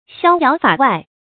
逍遥法外 xiāo yáo fǎ wài
逍遥法外发音